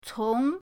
cong2.mp3